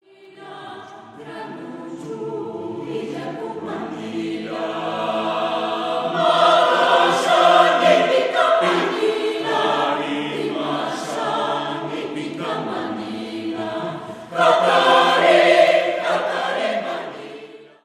Coro Mixto Fragmento (audio/mpeg)
Coro mixto